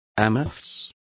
Complete with pronunciation of the translation of amorphous.